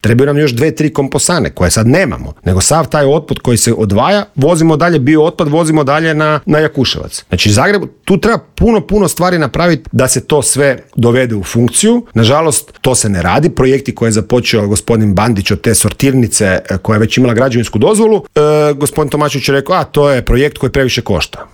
u studiju Media servisa